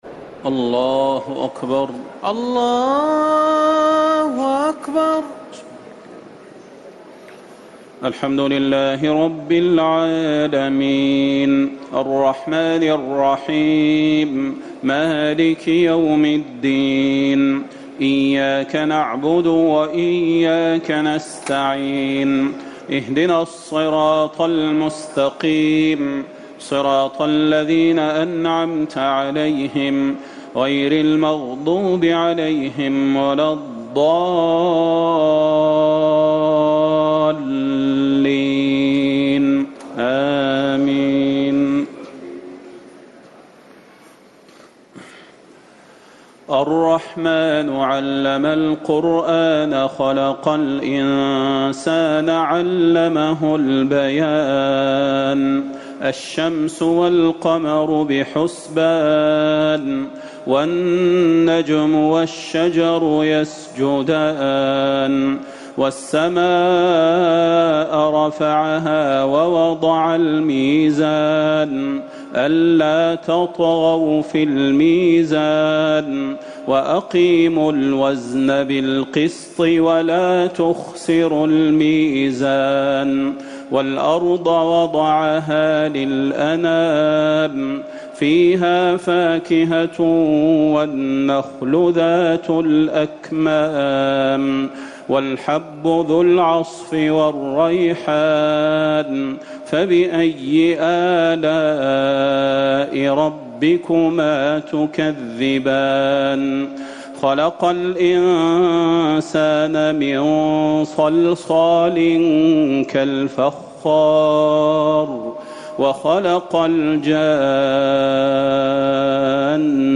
تراويح ٢٦ رمضان ١٤٤٠ من سورة الرحمن - الحديد > تراويح الحرم النبوي عام 1440 🕌 > التراويح - تلاوات الحرمين